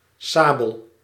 Ääntäminen
IPA: /ˈsvart/ IPA: [ˈsvaʈ]